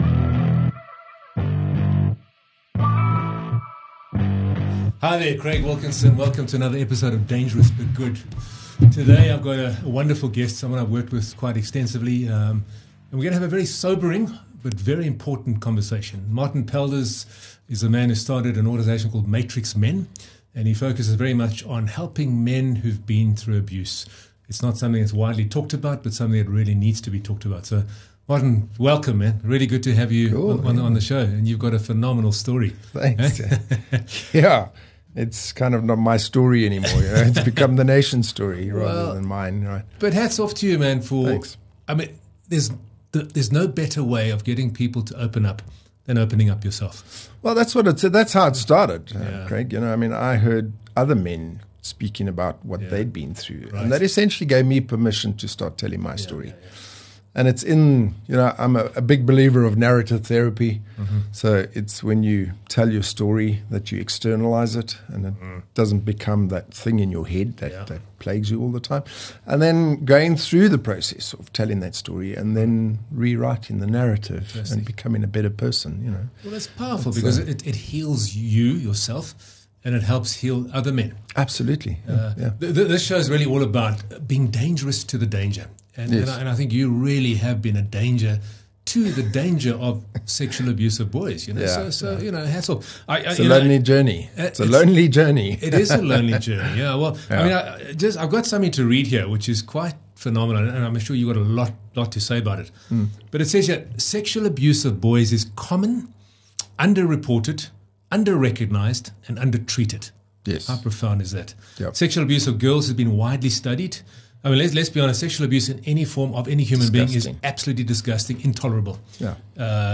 a survivor and activist